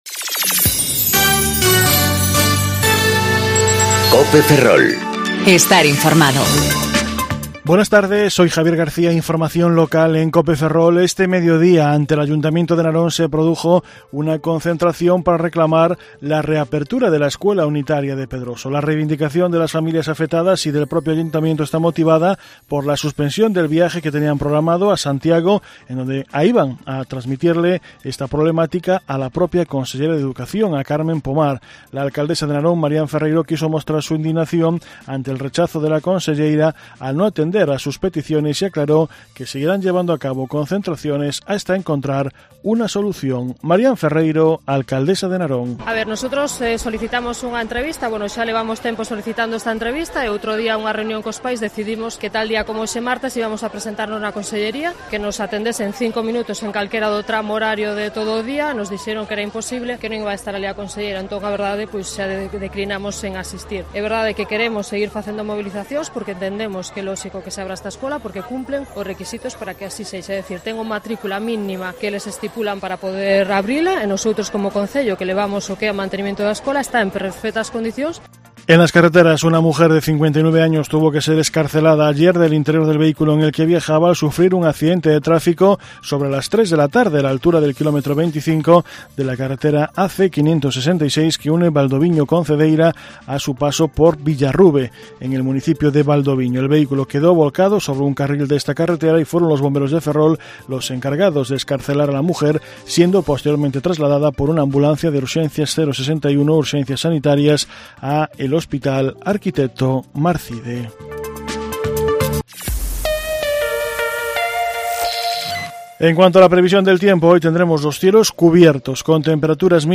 Informativo Mediodía Cope Ferrol 6/8/2019 (De 14.20 a 14.30 horas)